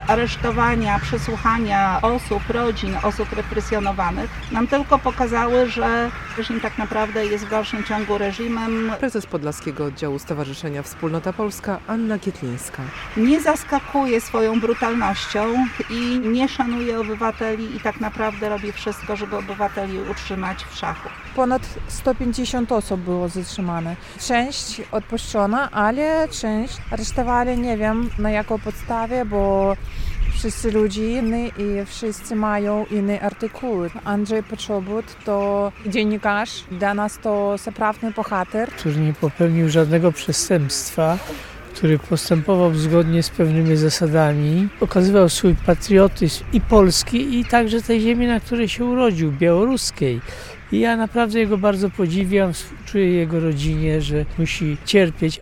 To spotkanie było gestem solidarności z więzionym Andrzejem Poczobutem i innymi zatrzymanymi przez białoruski reżim. W centrum Białegostoku protestowało w tej sprawie kilkanaście osób.
Solidarność z Andrzejem Poczobutem - relacja